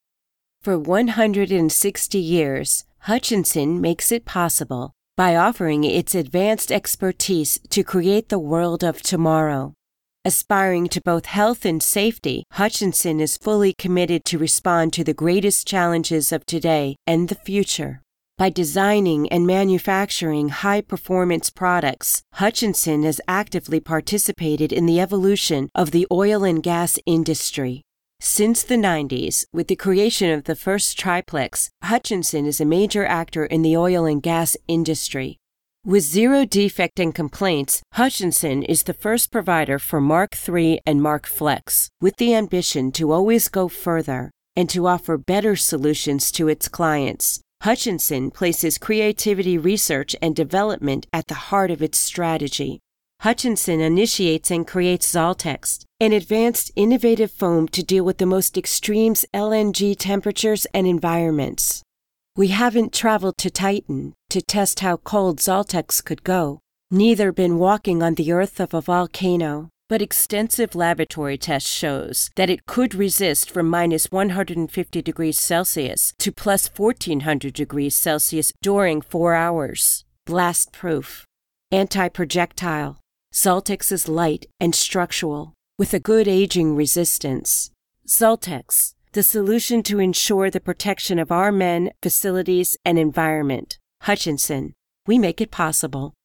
friendly, relaxed, authoritative, believable
Sprechprobe: Sonstiges (Muttersprache):